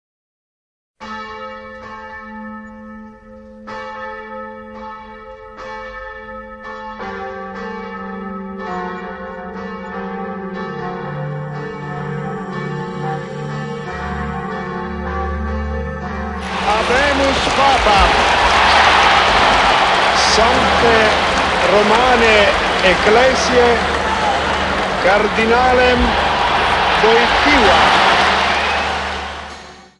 Nauczyliśmy się naszej Ojczyzny - wypowiedzi Ojca Świętego do rodaków
We've Learned Our Homeland - The Holy Father's Statements to His Countrymen 1978—2002